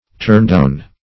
Turndown \Turn"down`\ (t[^u]rn"doun`), a.